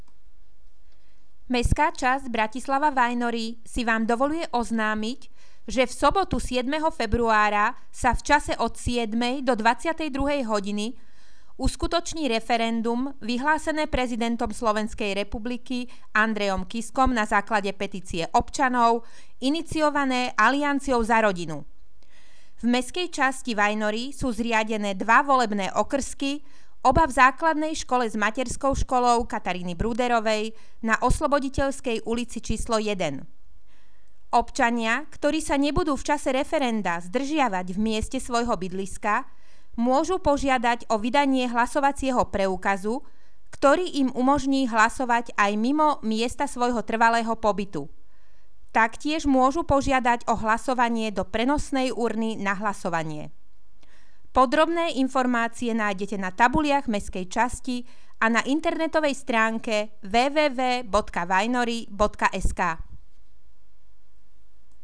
Hlásenie miestneho rozhlasu 30.,31.1.2015